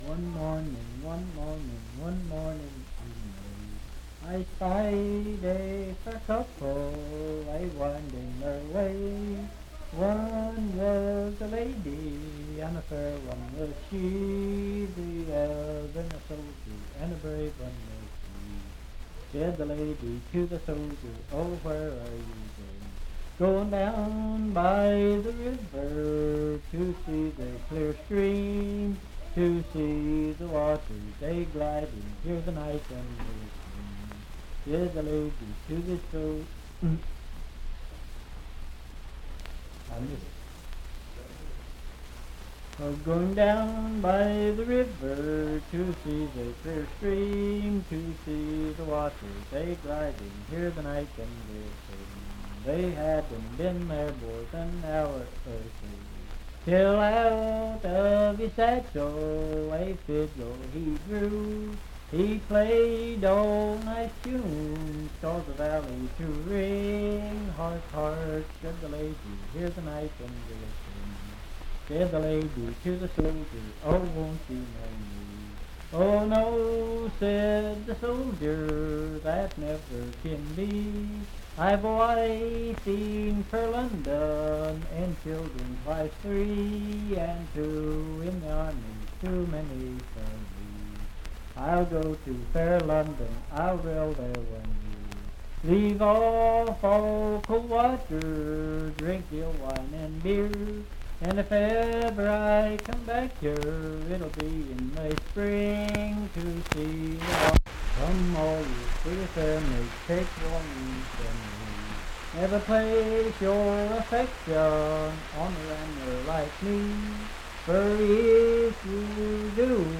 Unaccompanied vocal music
in Riverton, W.V.
Verse-refrain 6d(4).
Voice (sung)